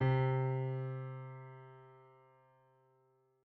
piano-sounds-dev
c2.mp3